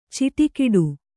♪ ciṭikiḍu